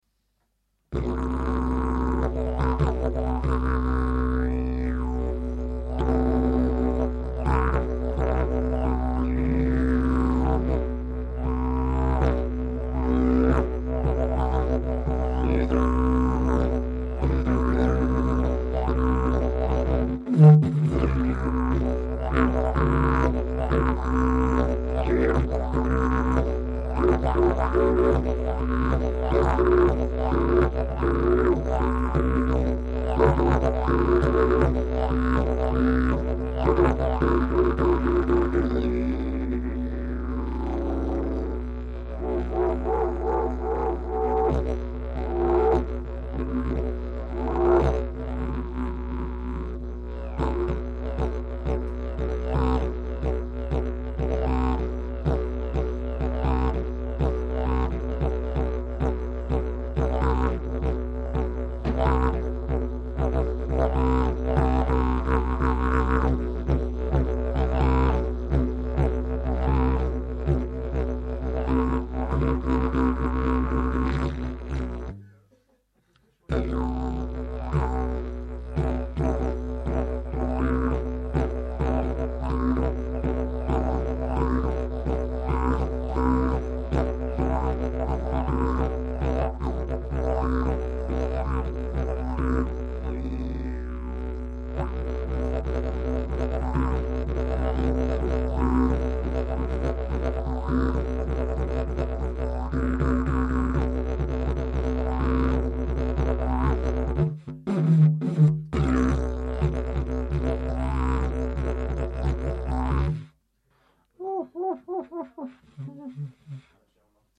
Il y a 2 Si, mais avant d'en causer plus, j'aimerais bien avoir vos avis sur leur son.
Le deuxieme moin nerveux selon les soundfile avec une plus grosse colomne d,air plus gros diametre je me trompe? as une son plus chaud moins crisp et plus full mais moin de briliance et legerement moins nerveux . ca cest avec ce que j,entend et dieu sais comment on peut twister un son de didge en enregistrant .
J'ai ma préférence pour le 2ème : plus chaleureux, plus de volume et j'ai aussi l'impression que les harmoniques sont plus présentes....
le deuxième résonne "occidental" avec un wak caractéristique et des harmonique plus chaudes, de forme il est surement plus large et verni.
Perso, je prefère le son du 2ème; le son est moins étouffé et les harmoniques se détachent plus clairement.
didge2.mp3